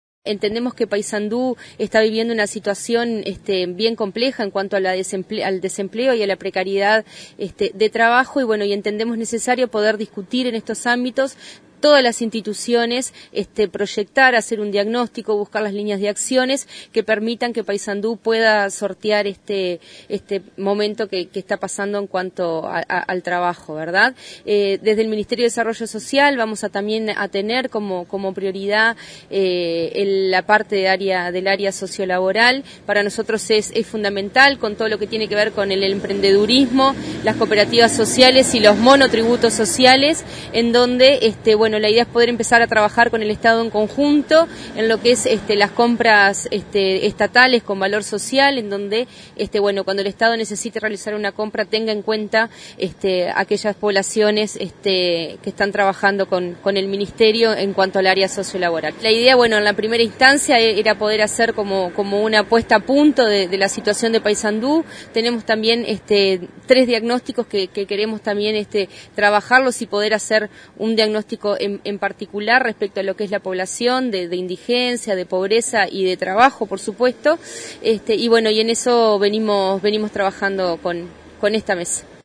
Informe: